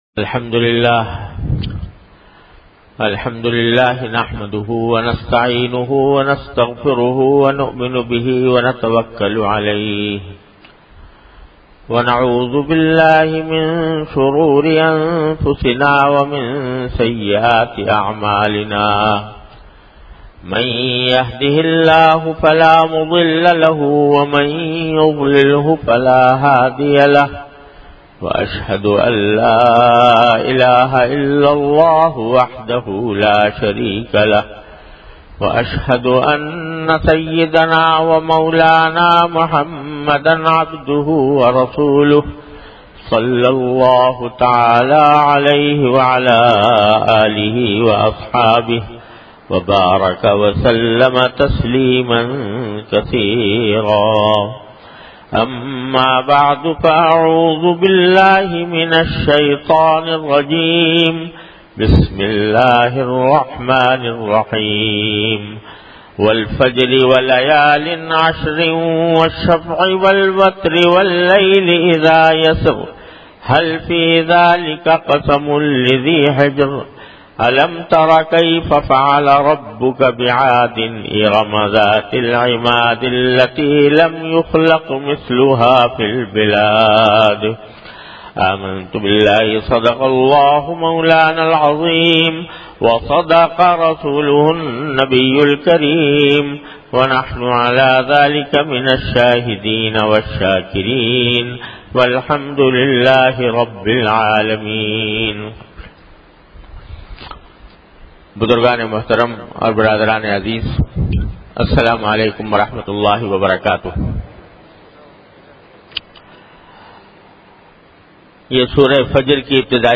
An Islamic audio bayan by Hazrat Mufti Muhammad Taqi Usmani Sahab (Db) on Tafseer. Delivered at Jamia Masjid Bait-ul-Mukkaram, Karachi.